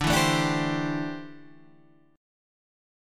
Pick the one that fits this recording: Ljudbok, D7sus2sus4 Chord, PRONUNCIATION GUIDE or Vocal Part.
D7sus2sus4 Chord